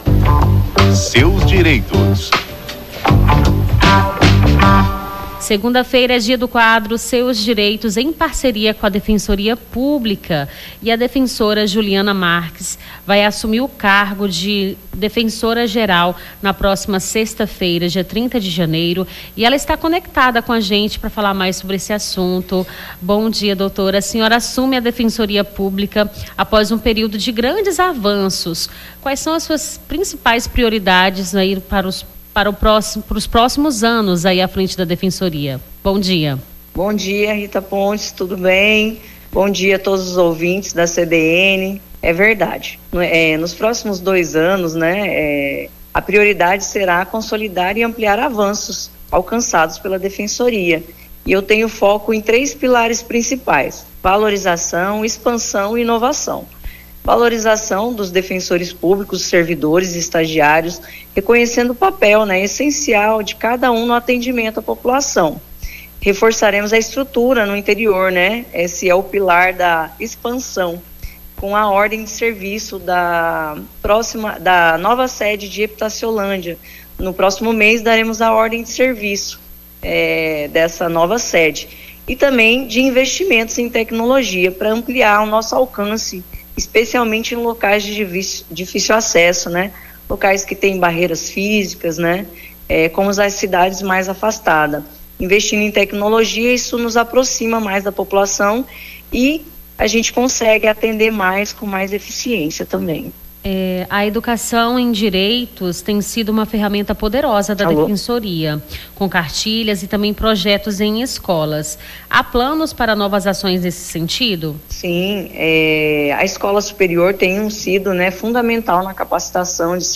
Seus Direitos: nova defensora geral fala sobre ações em 2025 na Defensoria Pública do Acre